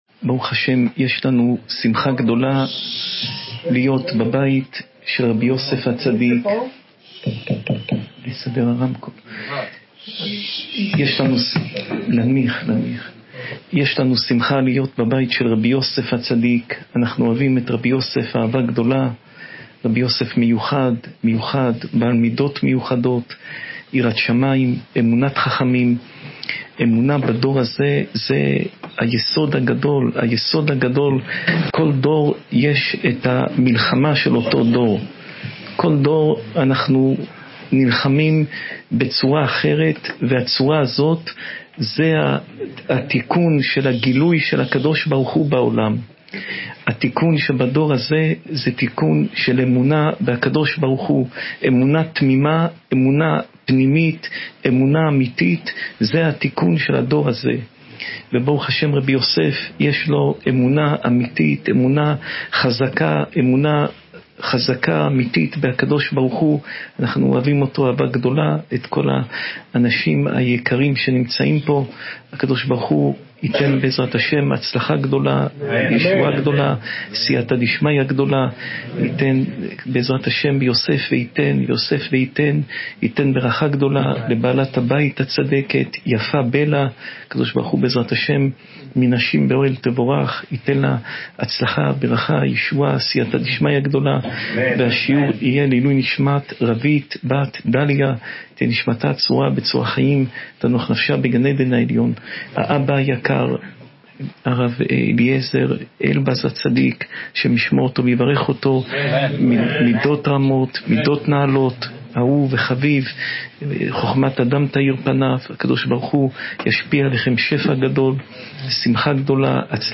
שעורי תורה מפי הרב יאשיהו יוסף פינטו